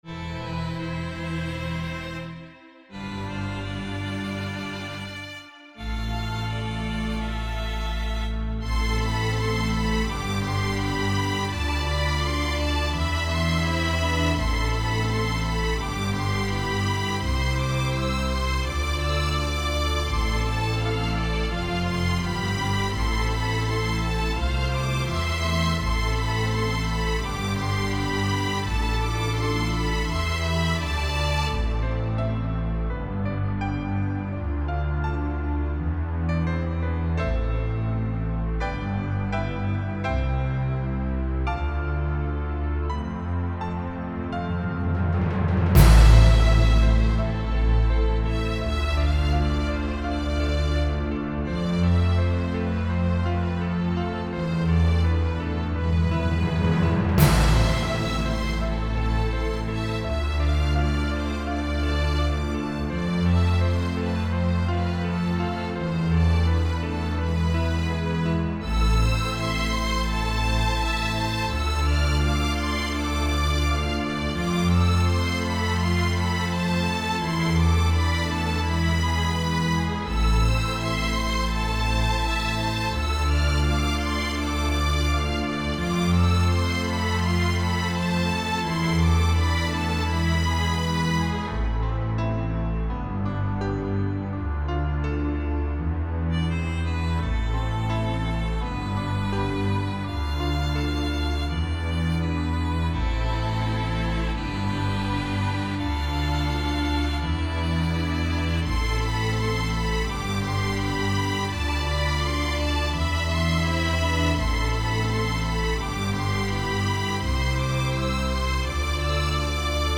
Ohes for piano & violin